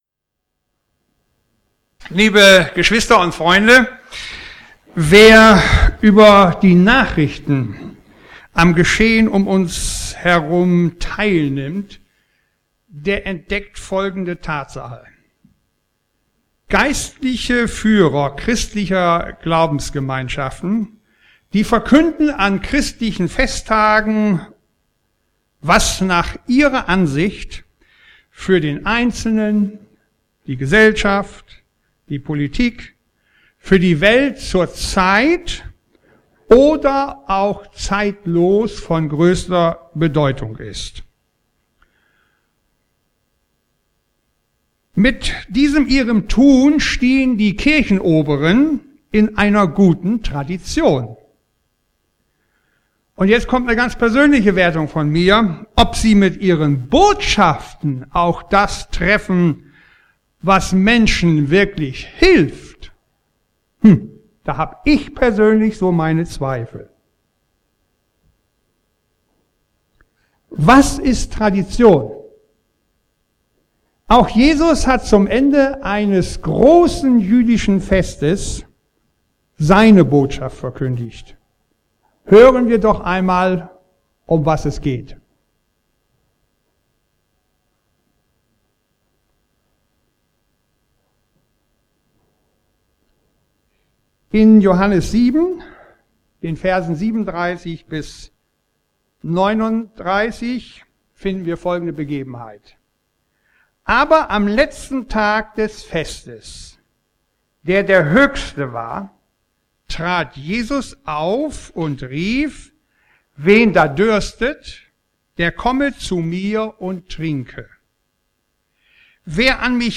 spricht in seiner Predigt vom 17. Juli 2011 über Johannes 7, 37-39.